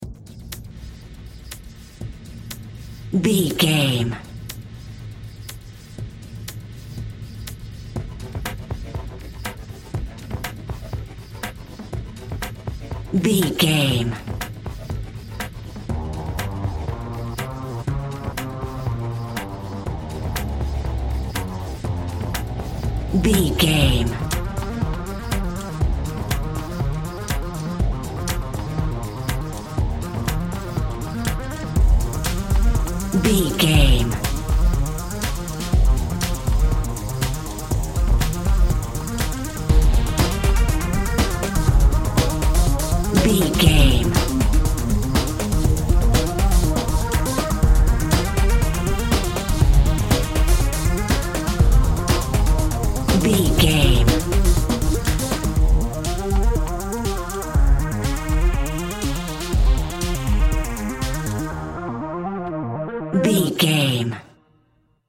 Epic / Action
Fast paced
In-crescendo
Ionian/Major
E♭
groovy
dark
futuristic
funky
energetic
driving
synthesiser
drum machine
house
techno
electro house
synth leads
synth bass